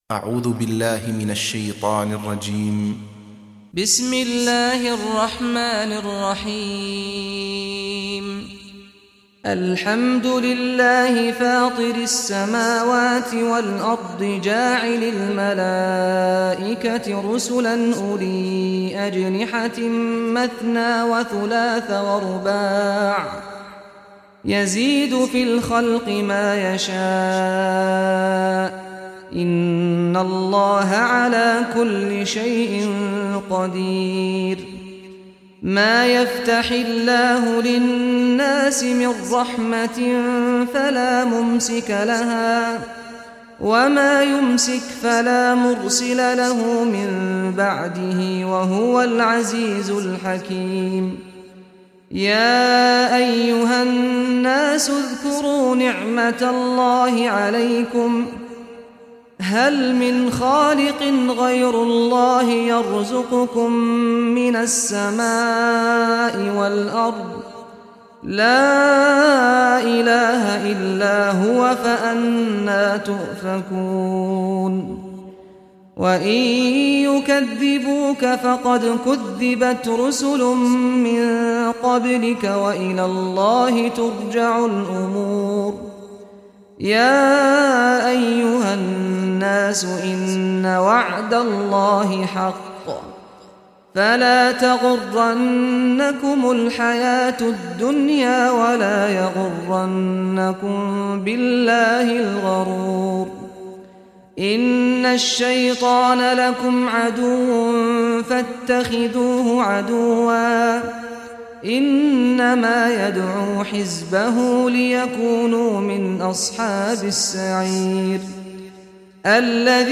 Surah Fatir Recitation by Sheikh Saad al Ghamdi
Surah Fatir, listen or play online mp3 tilawat / recitation in Arabic in the beautiful voice of Sheikh Saad al Ghamdi.